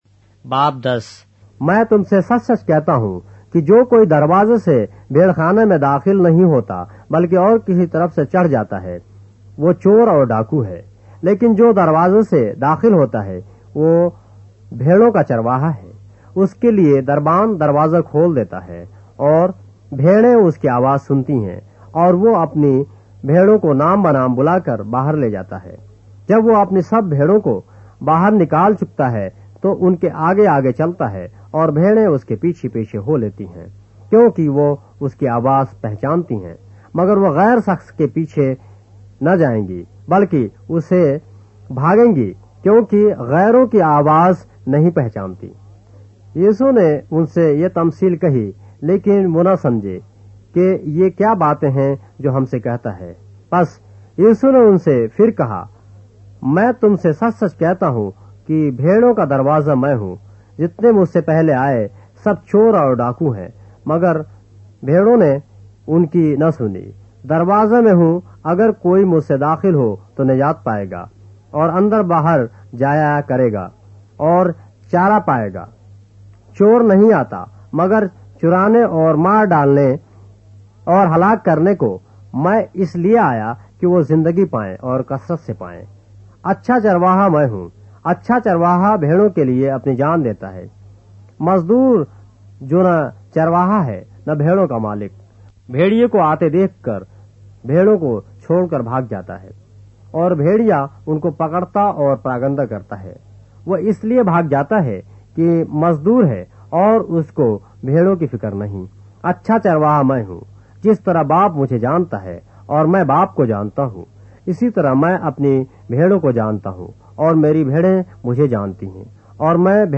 اردو بائبل کے باب - آڈیو روایت کے ساتھ - John, chapter 10 of the Holy Bible in Urdu